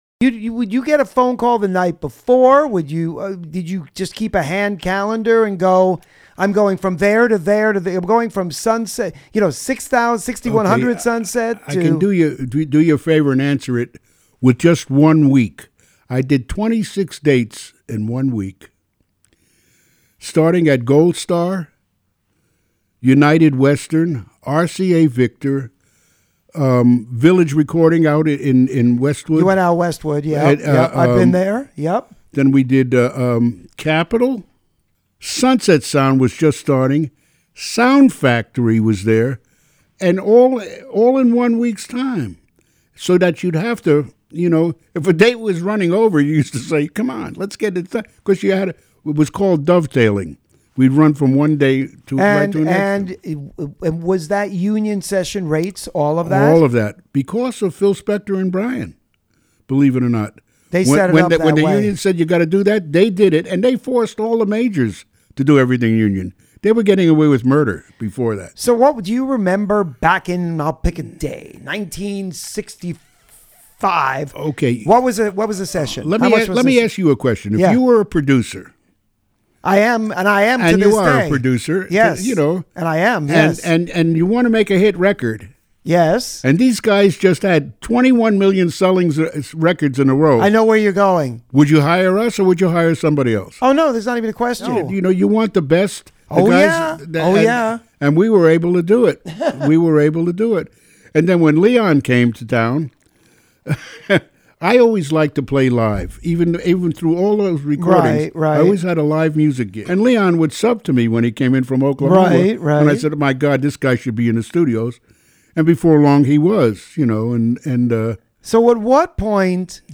This Week's Interview (02/02/2025):